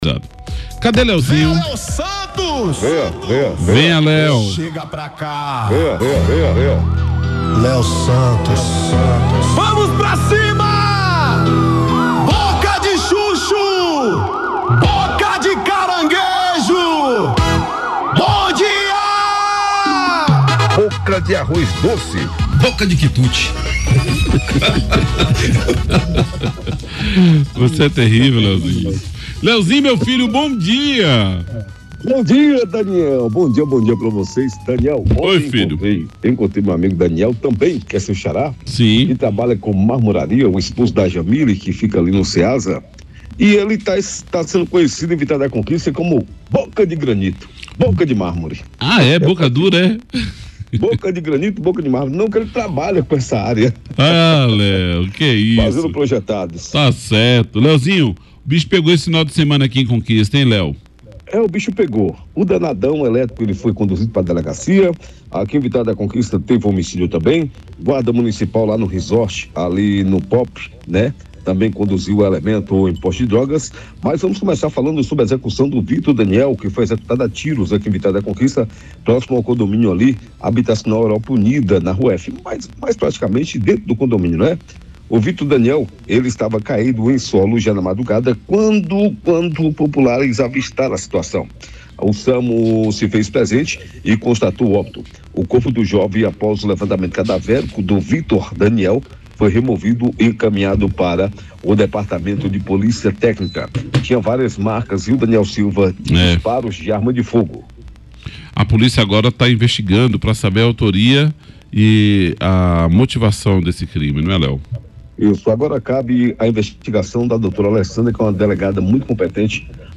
Na reportagem sonora, também são apresentadas informações sobre um suspeito de furtar baterias de carros, que acabou capturado e levado ao Distrito Integrado de Segurança Pública (DISEP).